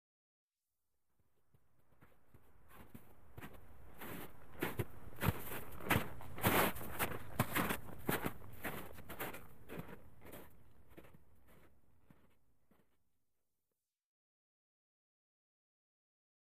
FootstepsSnowBy WES094901
Snow Hiking; Snow Walk By With Boots.